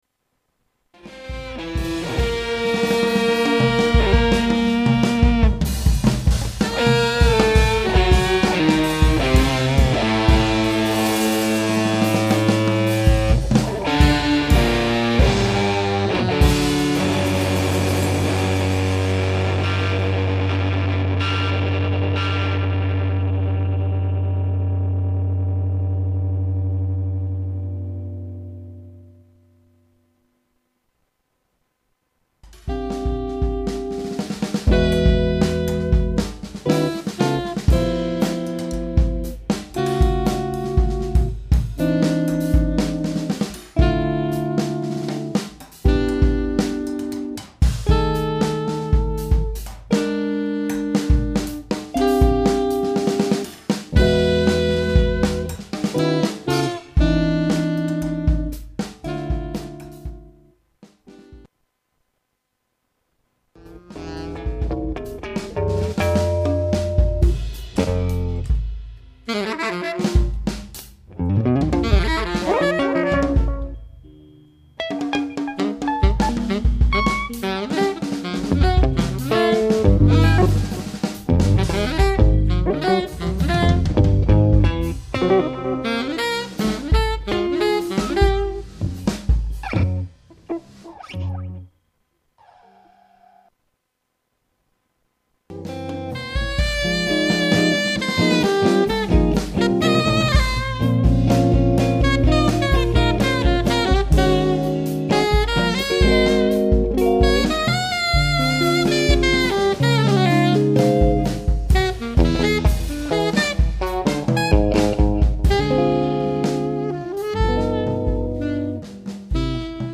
Schlagzeug
Altsaxophon
E - Gitarre